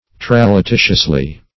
Search Result for " tralatitiously" : The Collaborative International Dictionary of English v.0.48: Tralatitiously \Tral`a*ti"tious*ly\, adv.